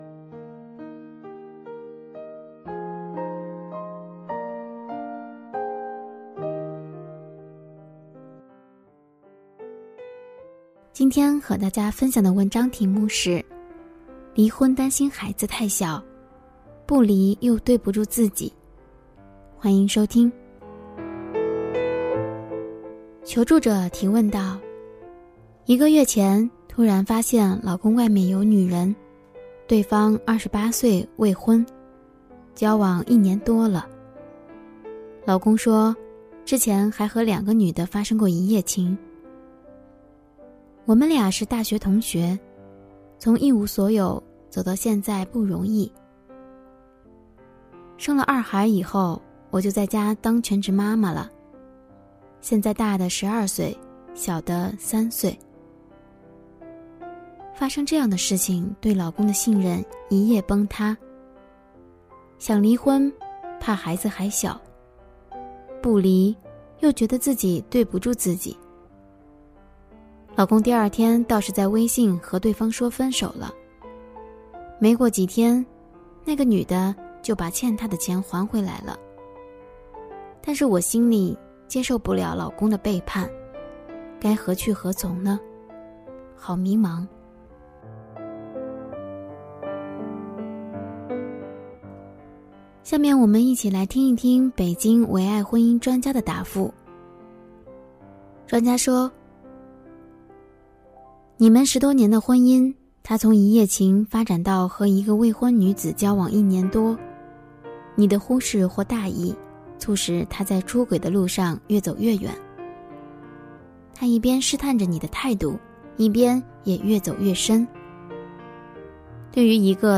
首页 > 有声书 > 婚姻家庭 > 单篇集锦 | 婚姻家庭 | 有声书 > 离婚担心孩子太小不离又对不住自己